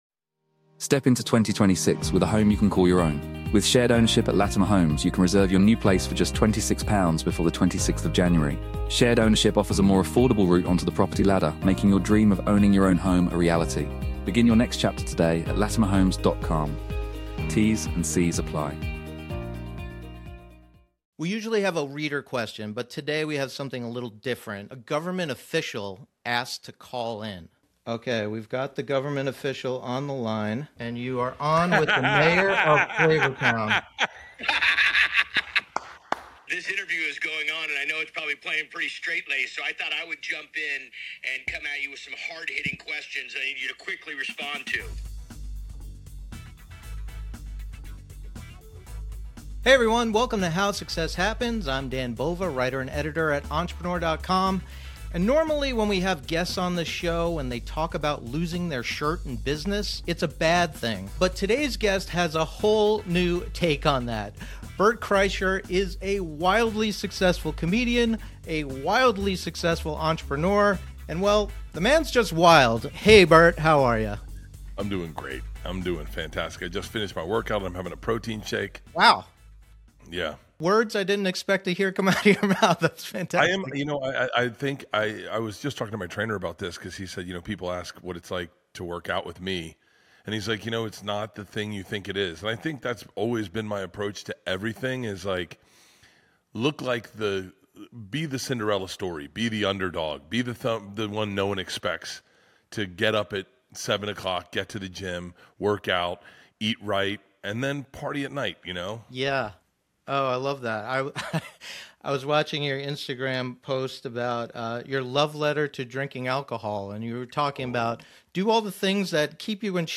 In this conversation, the stand-up comic, podcasting powerhouse and star of the new Netflix series Free Bert, talks about imposter syndrome and what actually drives his relentless work ethic. He shares his formula for telling a great story and explains why the most awkward celebrity interactions often make the best ones. Plus, Guy Fieri drops in to ask Bert a few questions of his own.